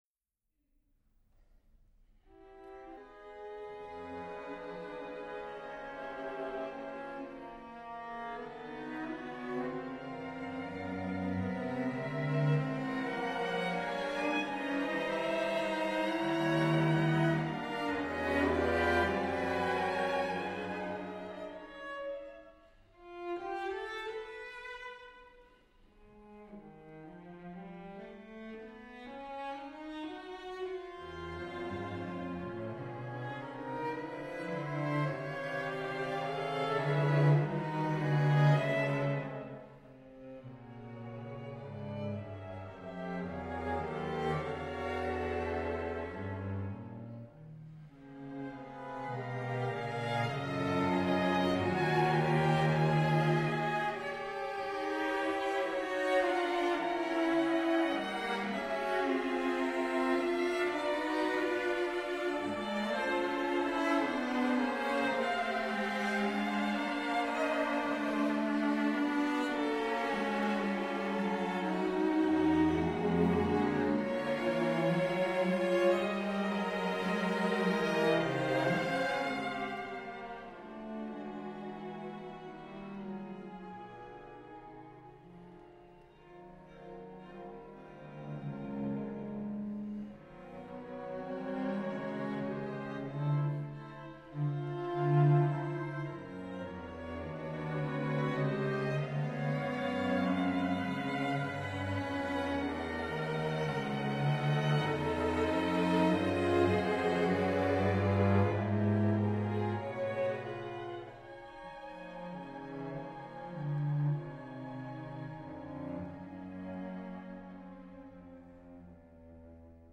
for 6 cellos